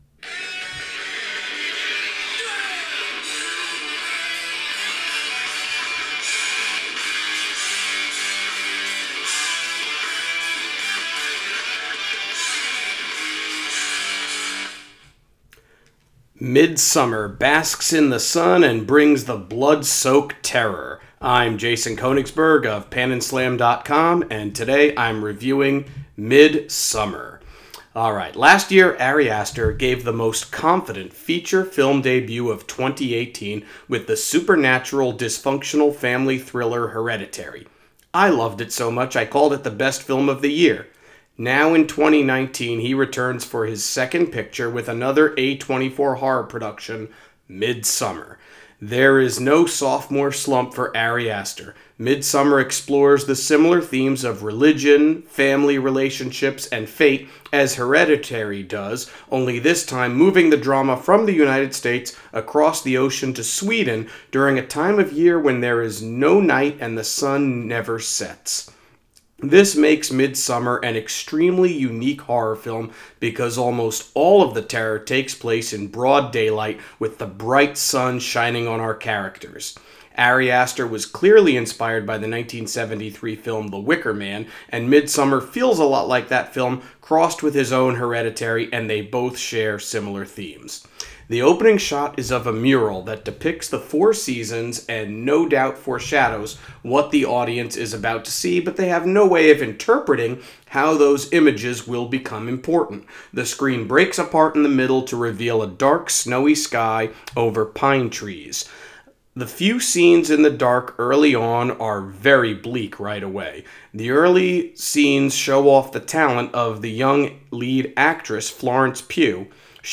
Movie Review: Midsommar